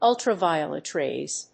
アクセントúltraviolet ráys
ウルトラバイオレット‐レイ